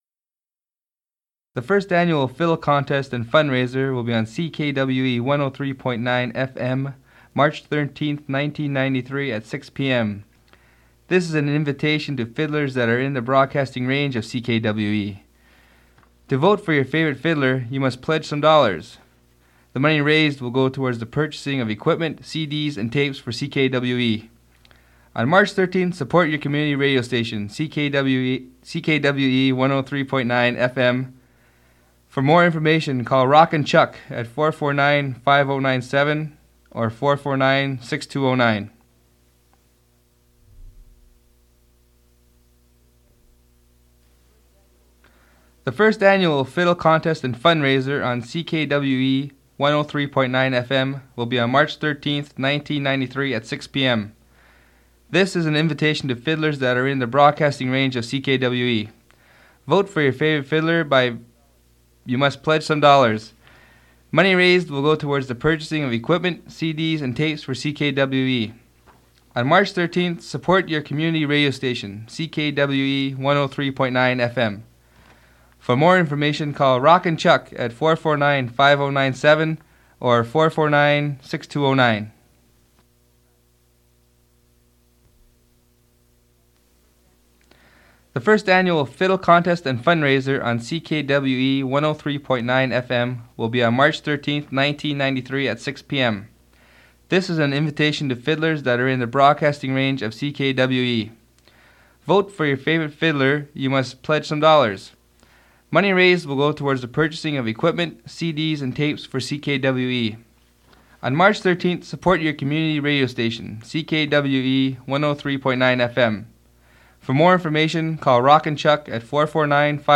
Fait partie de Violin competition and fundraiser